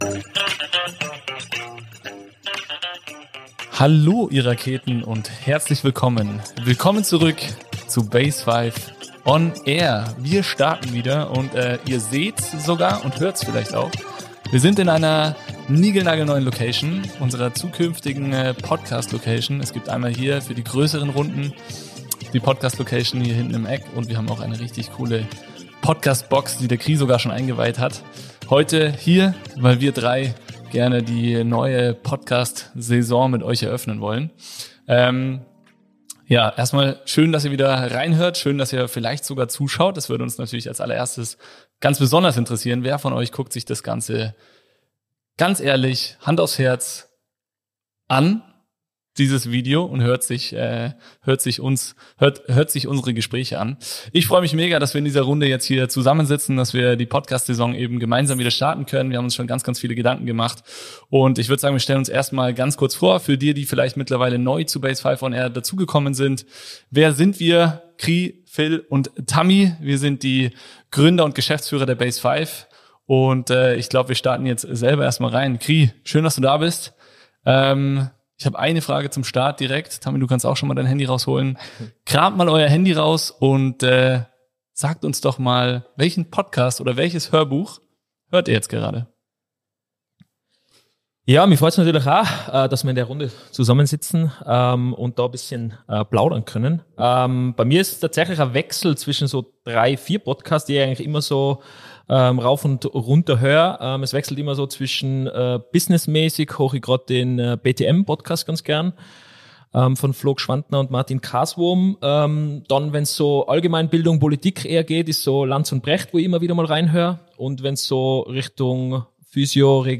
Beschreibung vor 1 Jahr BASEFIVE on air ist zurück – und das in einer brandneuen Podcast-Location!